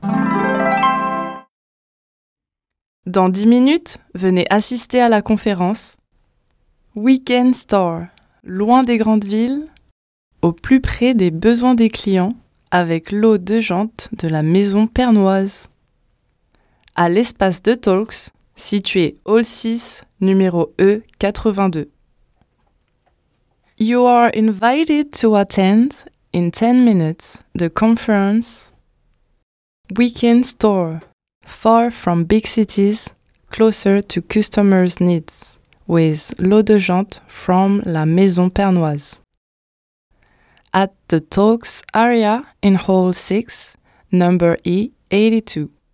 Annonces